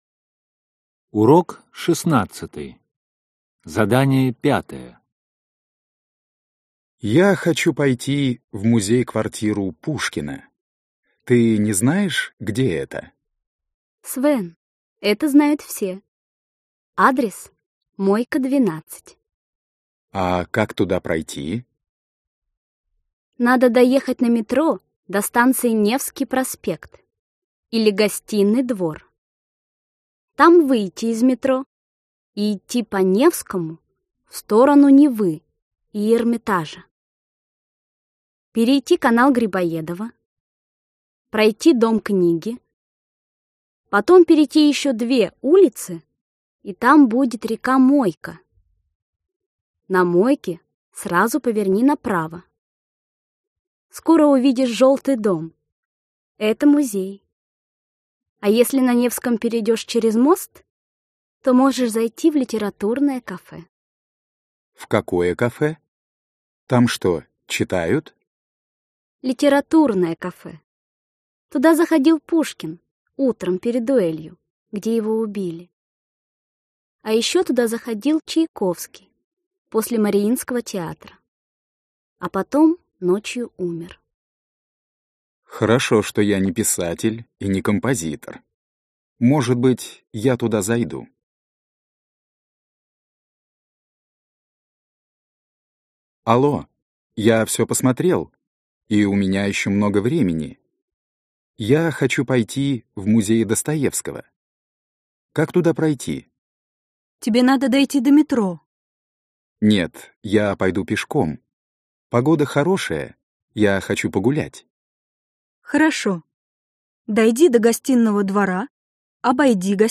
Аудиокнига Поехали. Ч 2.Т 2. Базовый курс | Библиотека аудиокниг